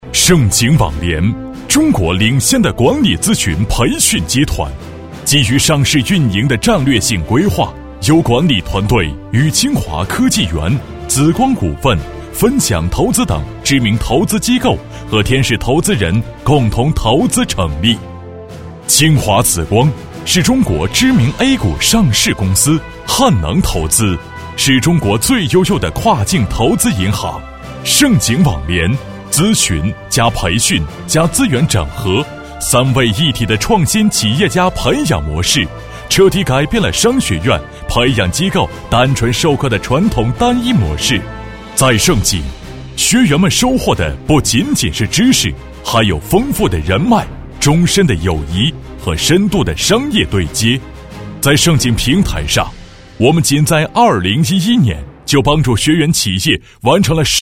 当前位置：首页 > 配音题材 > 宣传片配音
男声配音